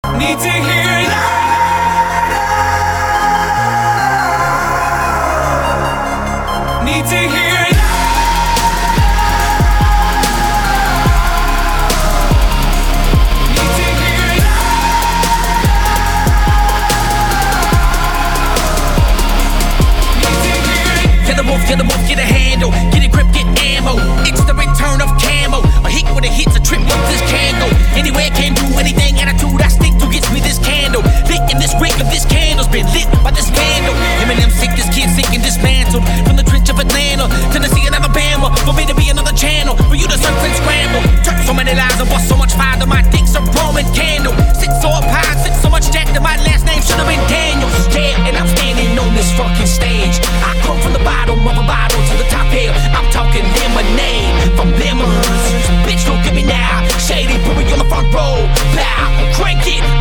• Качество: 320, Stereo
качающие
Rap
Bass